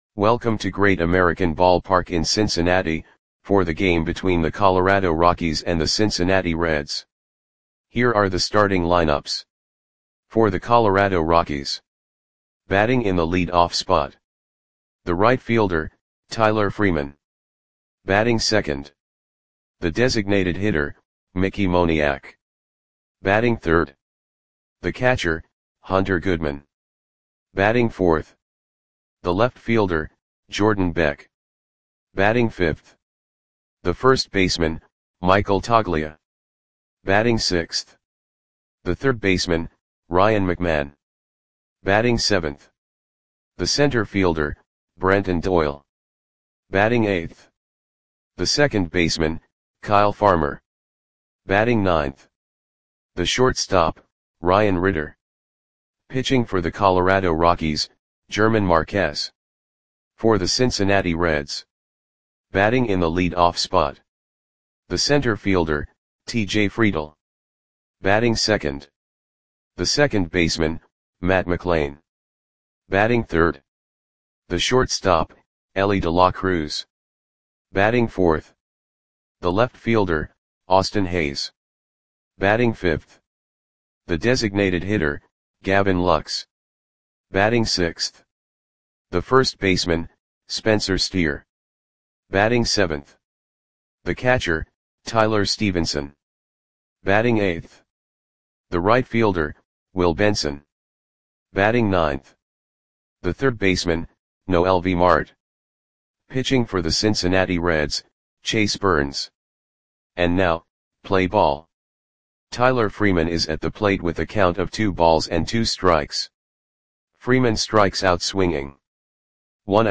Audio Play-by-Play (back to top)